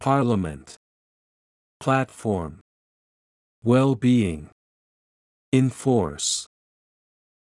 音声を再生し、強勢のある母音（＝大きな赤文字）を意識しながら次の手順で練習しましょう。
Parliament /ˈpɑːrləˌmənt/（名）議会、国会
platform /ˈplætˌfɔːrm/（名）プラットフォーム、基盤
well-being /ˌwɛlˈbiːɪŋ/（名）幸福、健康、福祉
enforce /ɪnˈfɔːrs/（動）施行する、強制する